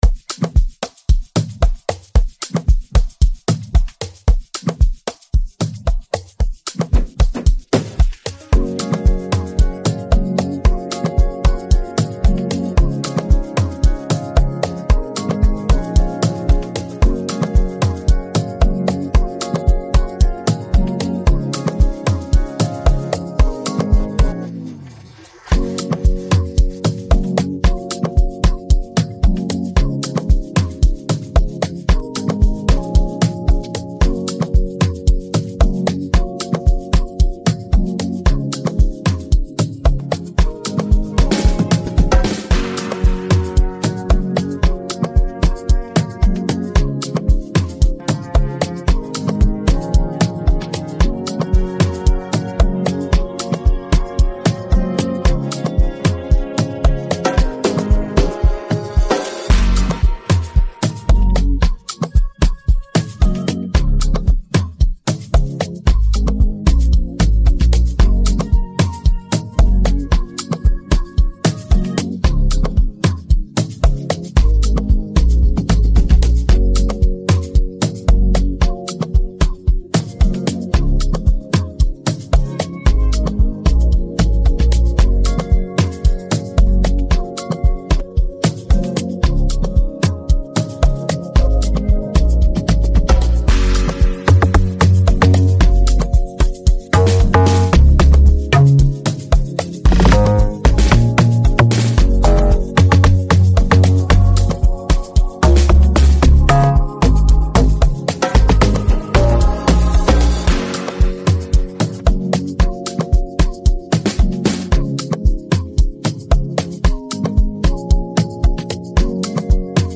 Tagged afrobeats , amapiano